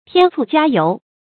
添醋加油 注音： ㄊㄧㄢ ㄘㄨˋ ㄐㄧㄚ ㄧㄡˊ 讀音讀法： 意思解釋： 見「添油加醋」。